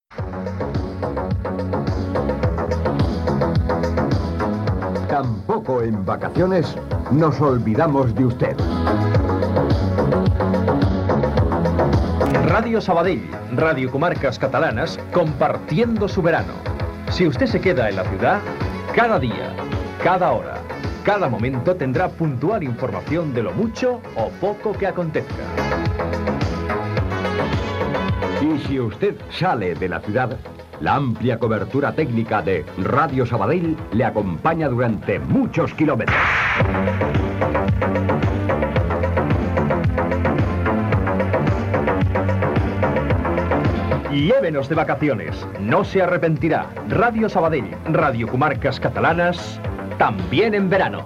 Promoció d'estiu de l'emissora